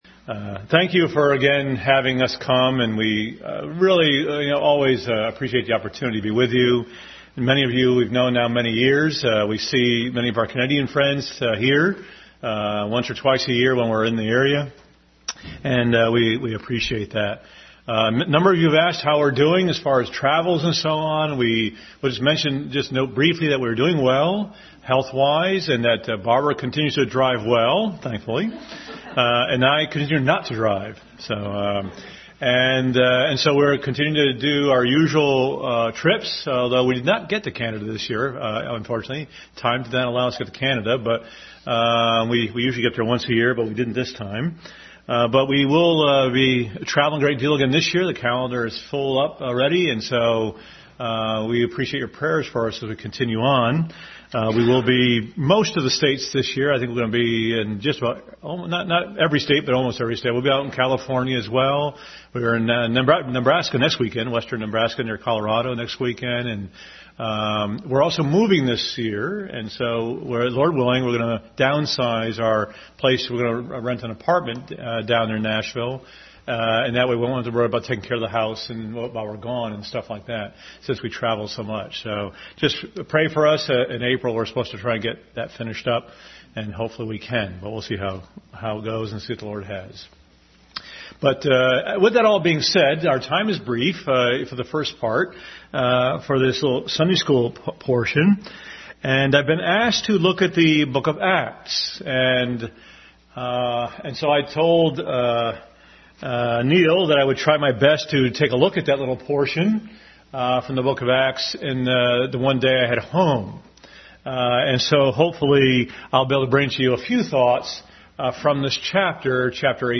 Acts 18:18-28 Service Type: Sunday School Bible Text